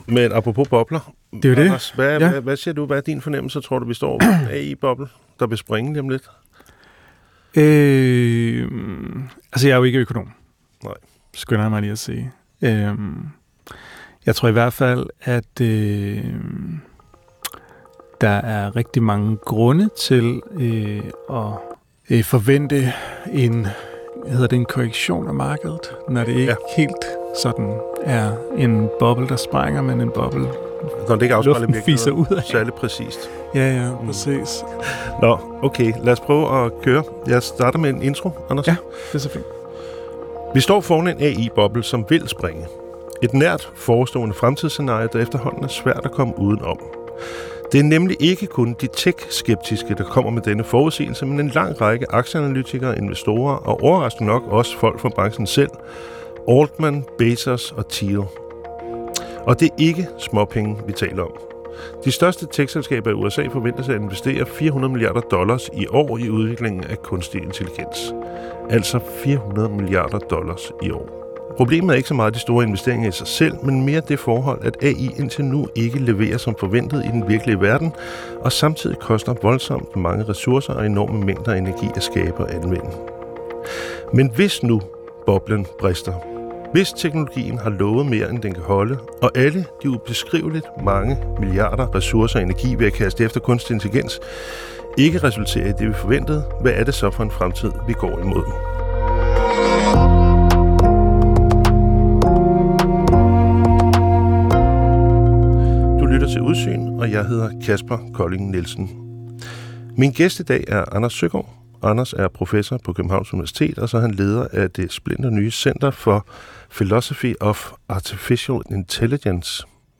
Er vi på vej mod kaos og verdenskrig eller en mere retfærdig og fredelig verden? Hver weekend udfolder vært og forfatter Kaspar Colling Nielsen sammen med landets førende eksperter tidens vigtigste temaer for at forsøge at forstå de kræfter og logikker, der styrer de dramatiske udviklinger i samfundet.